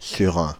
French pronunciation of « Surrain »
Fr-Paris--Surrain.ogg